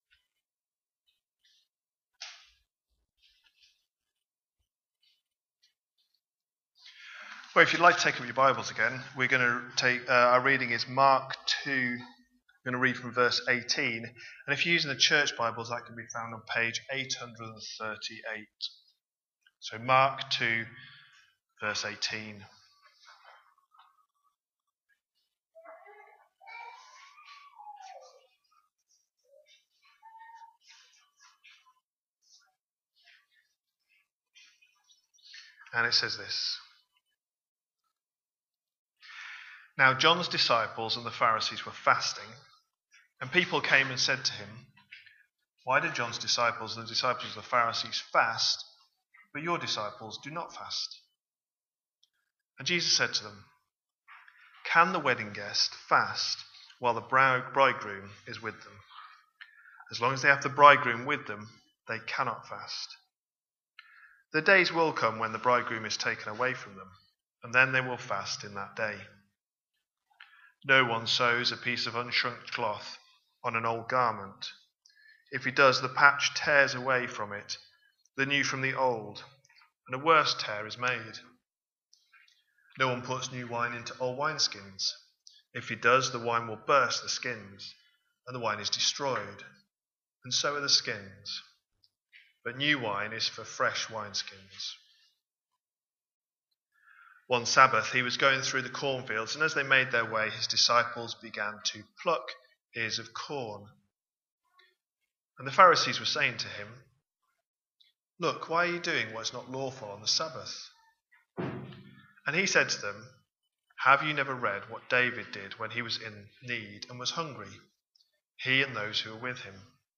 A sermon preached on 26th October, 2025, as part of our Mark 25/26 series.